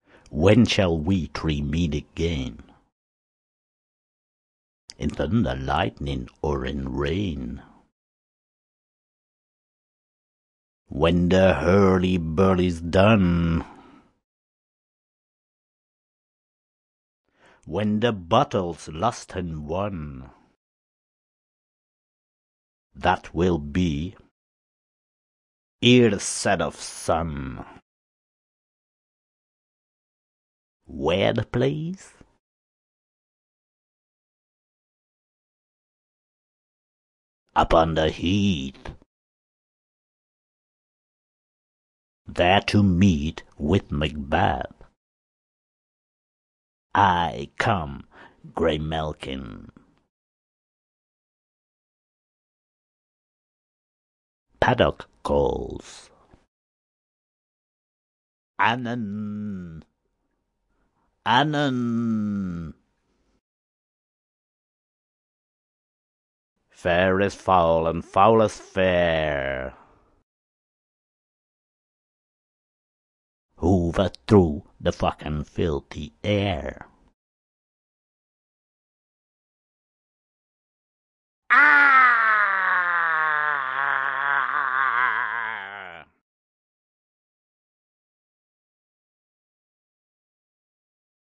标签： 声音 巫婆 莎士比亚 声乐 表演 麦克白
声道立体声